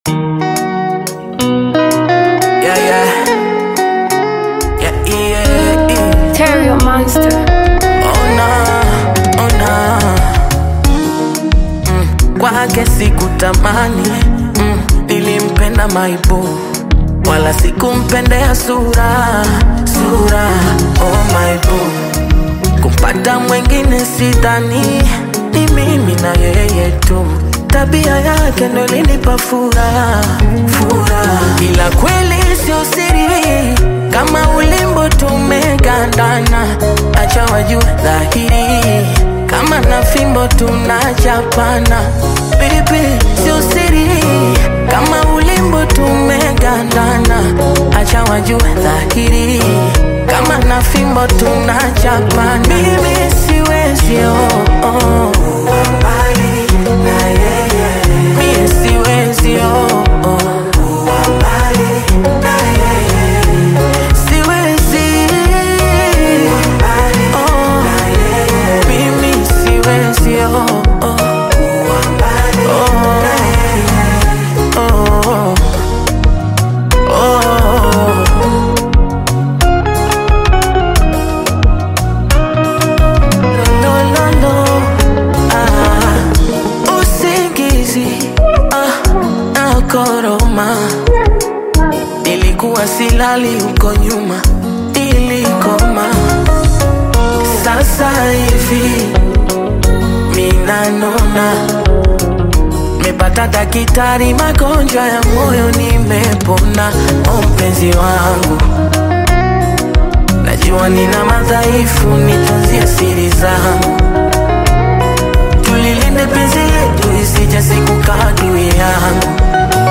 expressive vocals over clean, polished production